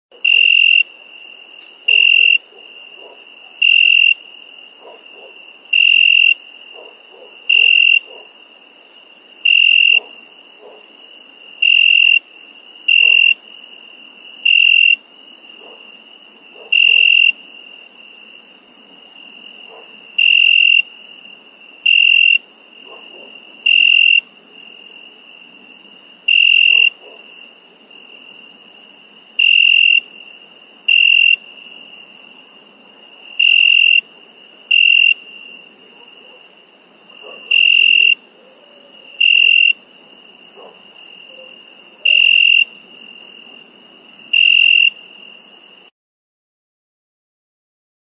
Category : Animals